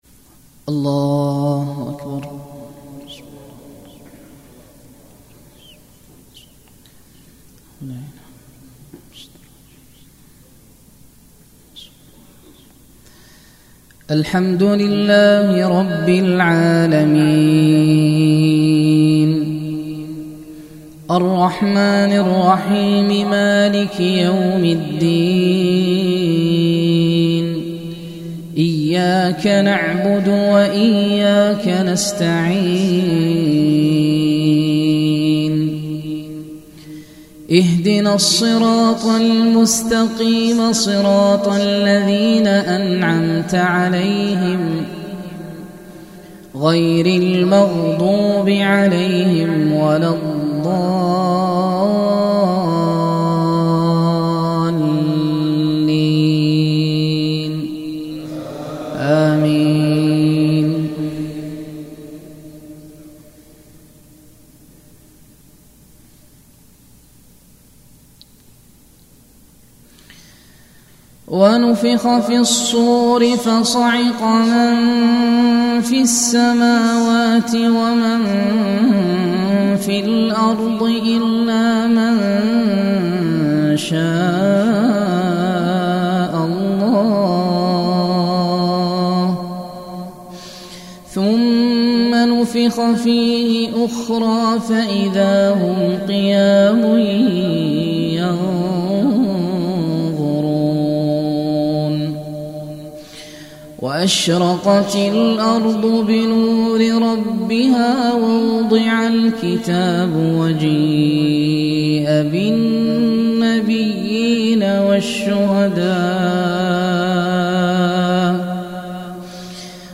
القرآن الكريم برواية حفص عن عاصم